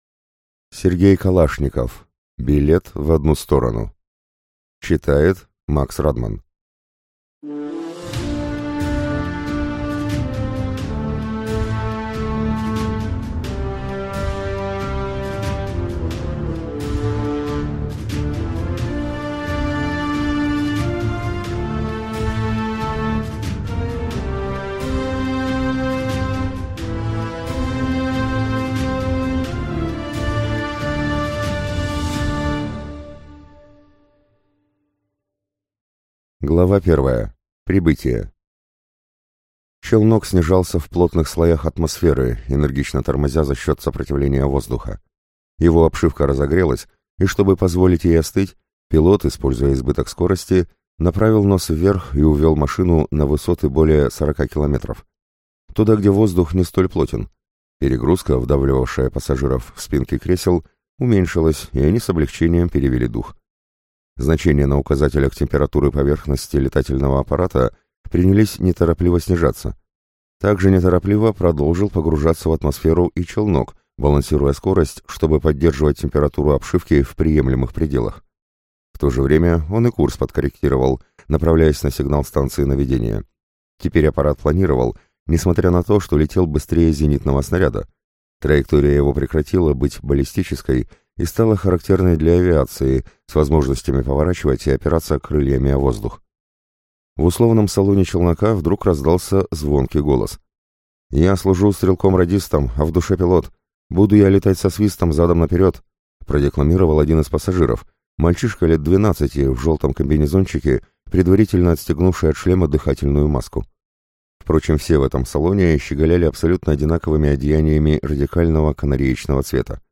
Аудиокнига Аудиокнига на Литрес 11 .04.23 боевая фантастика, космическая фантастика, иные миры Что может пойти не так при колонизации планеты?.. Колонизация планеты с кислородной атмосферой и богатым животным и растительным миром.